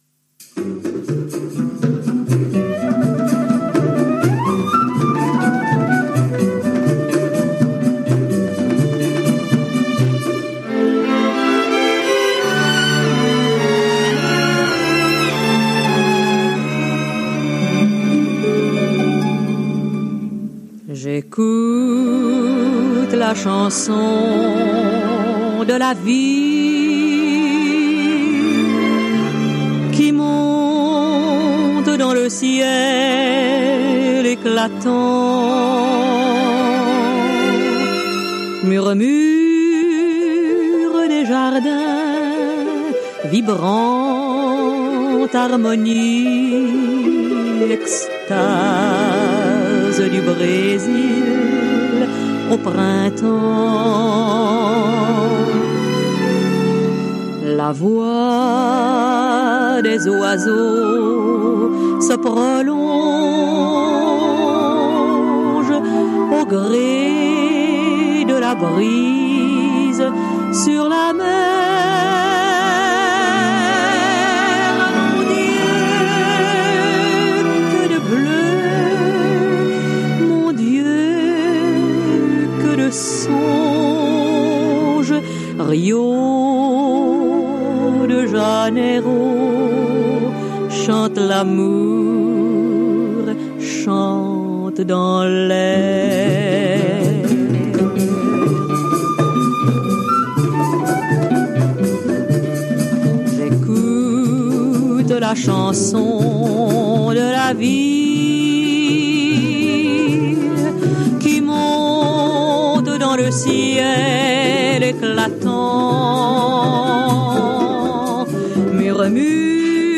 C’est une émission spéciale à thème que RADIO VINTAGE PLUS a diffusée le dimanche 23 mars 2025 à 10h en direct des studios de RADIO RV+ en BELGIQUE